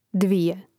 dvȉje dvije glav. br.